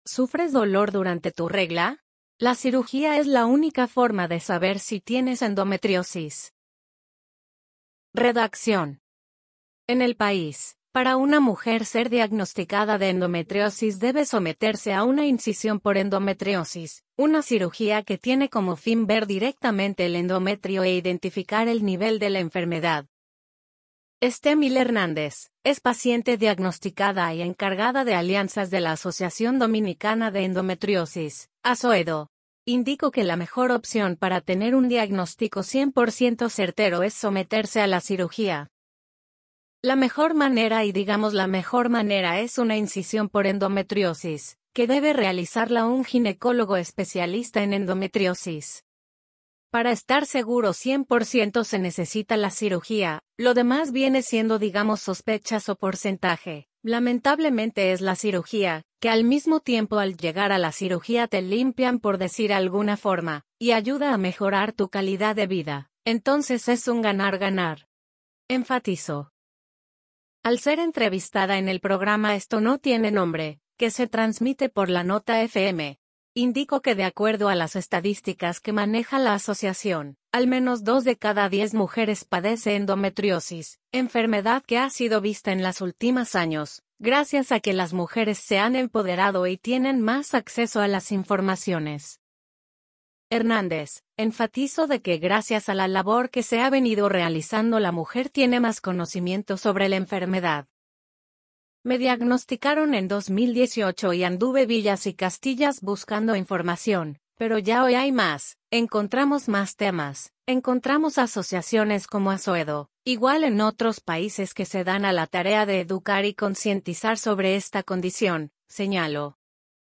Al ser entrevistada en el programa Esto No Tiene Nombre, que se transmite por la Nota FM, indico que de acuerdo   a las estadísticas que maneja la asociación, al menos 2 de cada 10 mujeres padece endometriosis, enfermedad que ha sido vista en las ultimas años, gracias a que las mujeres se han empoderado y tienen más acceso a las informaciones.